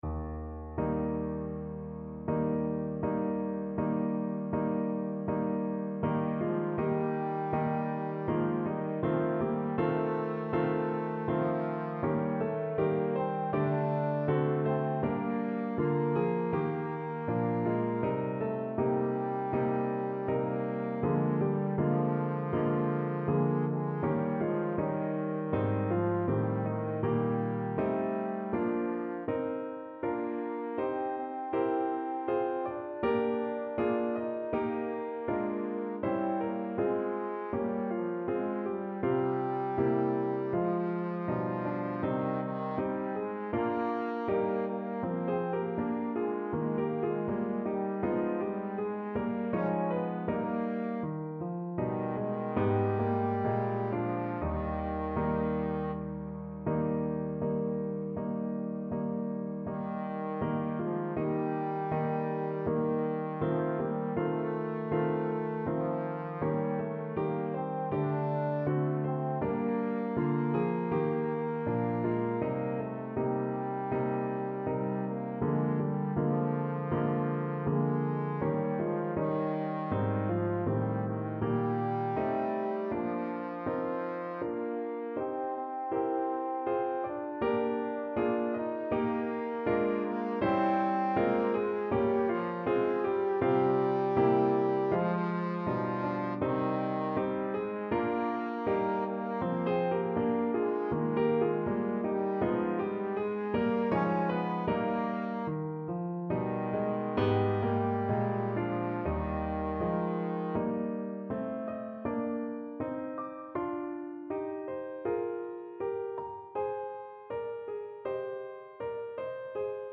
Trombone version
Andante
4/4 (View more 4/4 Music)
Eb4-Eb5
Classical (View more Classical Trombone Music)